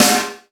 Snare 6.WAV